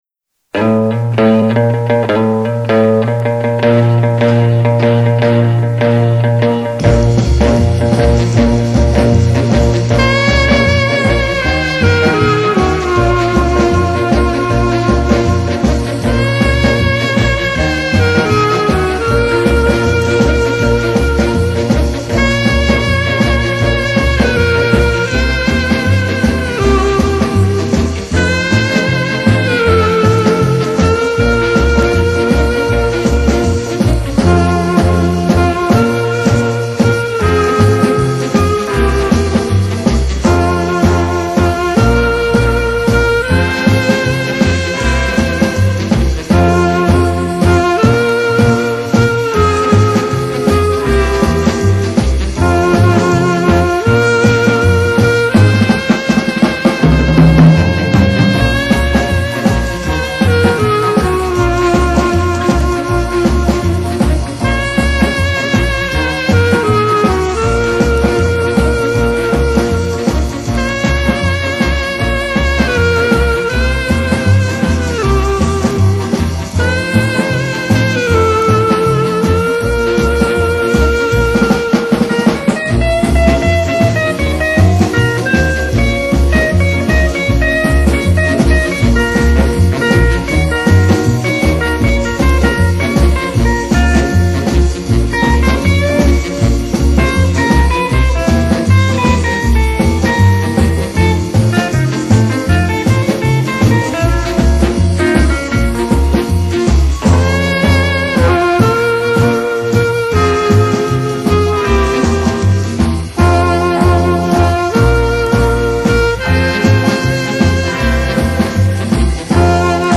鳴きのアルト・サックスが心を締め付ける。
まずモノラル録音であること、アルトの鳴きが抜群であること。
他との違いは、間奏のギターがダサ・カッコイイこと、
間奏が終わってサビから出るアルトが上の３音から出てること、
間奏の後、３度目のドラムのフィル・インがコケてること。
いやギターはともかく、ドラムはまるで素人のようだ。
とにかくアルトは素晴らしい。ほんとに良く鳴いてる。
例えばカセットからだとしても、左右トラックの揺れがひどく、
高いほう（シンバルなど）が出たり引っ込んだりしてる。
ガマンして高域を持ち上げて、レベルは歪む寸前まで上げてみた。
そして完全モノラルに修正した。